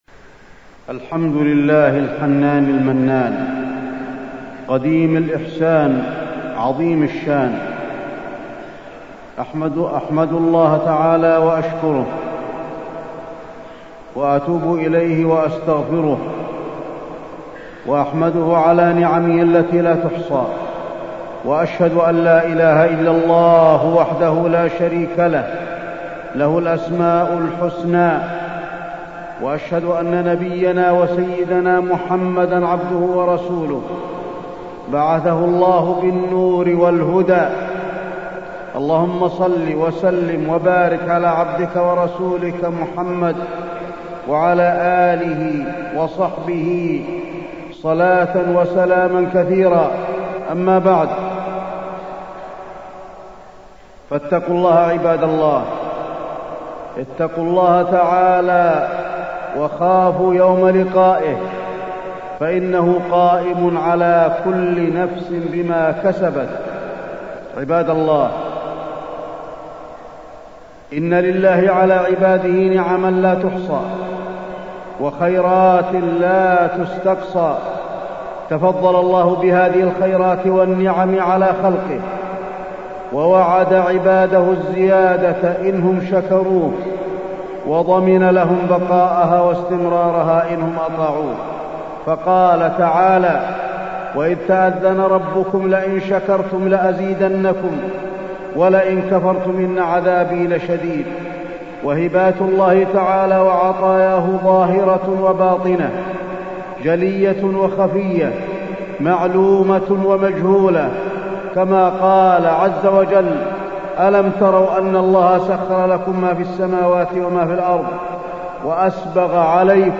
تاريخ النشر ١٢ ذو القعدة ١٤٢٥ هـ المكان: المسجد النبوي الشيخ: فضيلة الشيخ د. علي بن عبدالرحمن الحذيفي فضيلة الشيخ د. علي بن عبدالرحمن الحذيفي الإقتداء بالسلف الصالح